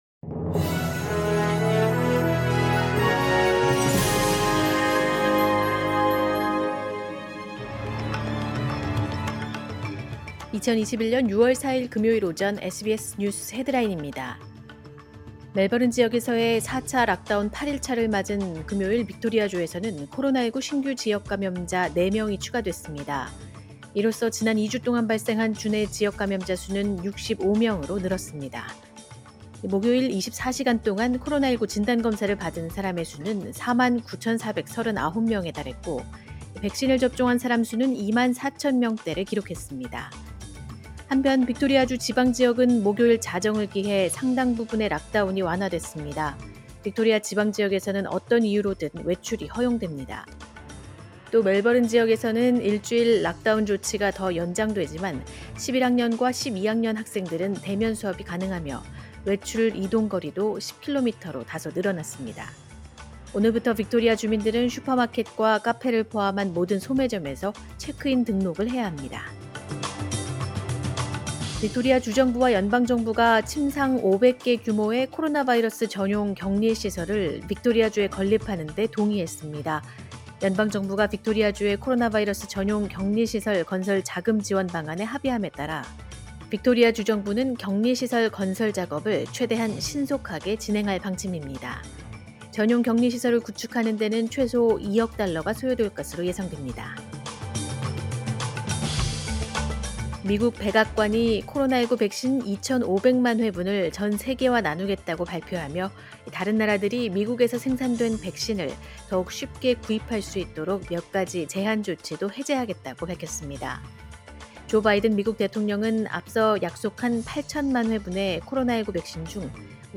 “SBS News Headlines” 2021년 6월 4일 오전 주요 뉴스
2021년 6월 4일 금요일 오전의 SBS 뉴스 헤드라인입니다.